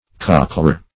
Search Result for " cochleare" : The Collaborative International Dictionary of English v.0.48: Cochleare \Coch`le*a"re\, n. [L.] 1.